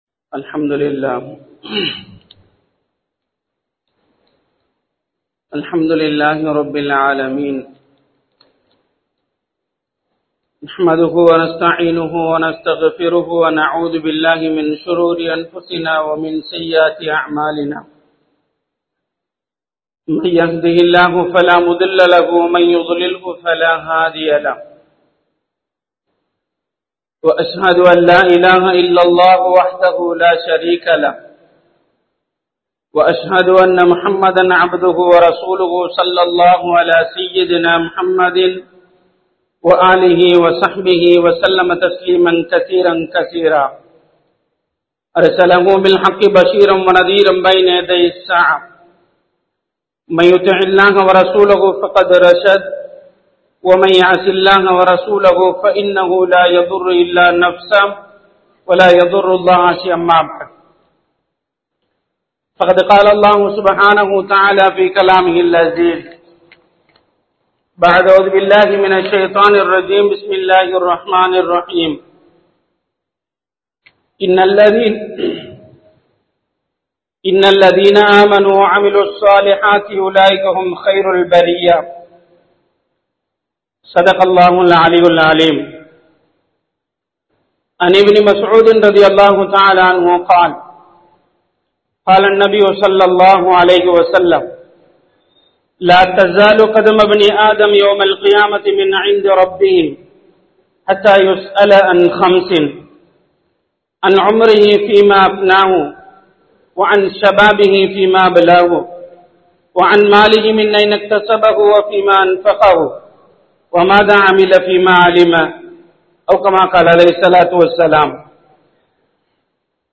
படைத்தவனை மறந்து விடாதீர்கள் | Audio Bayans | All Ceylon Muslim Youth Community | Addalaichenai
Pallimulla Jumua Masjith